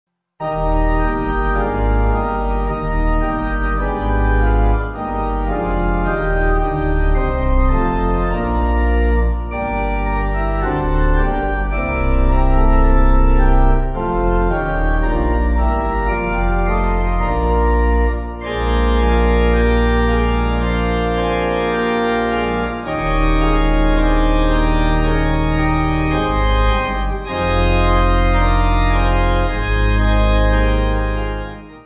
Faster than version in D